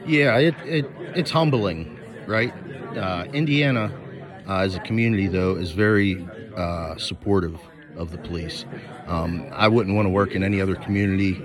Indiana’s law enforcements officials were recognized Friday afternoon at the George E. Hood Municipal building.